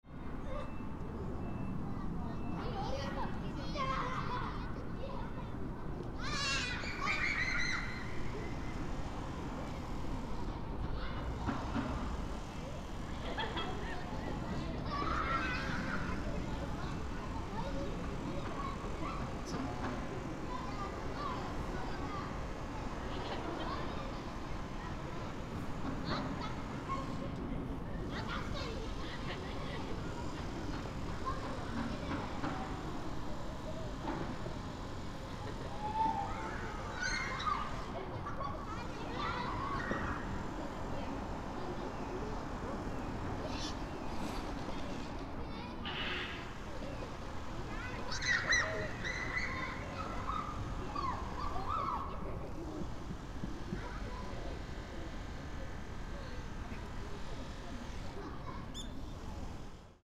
In unseasonably warm weather for Febrary, many children were playing in the park. ♦ Some high school students were practicing street dance around the monitoring spot. ♦ Some crows were cawing, and several birds were also twittering around the park.